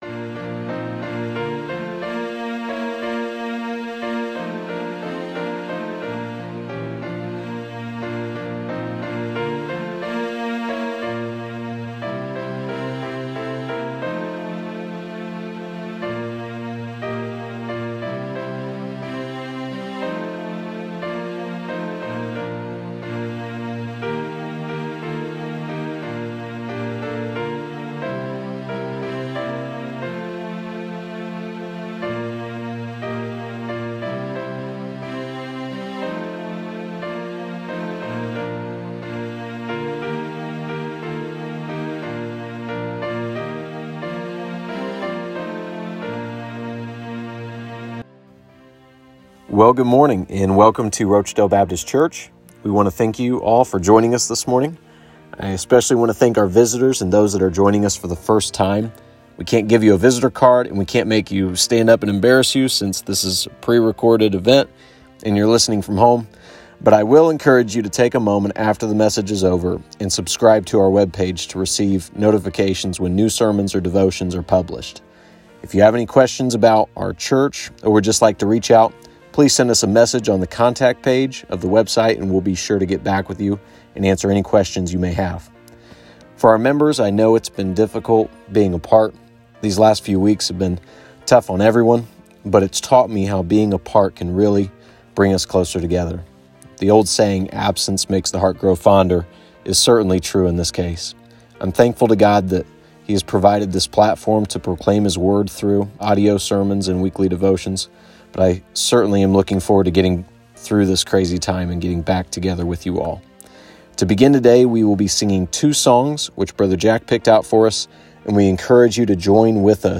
In the audio clip we included the following songs. Please join us in singing He Included Me, In the Sweet by and by, and special music: Amazing Grace.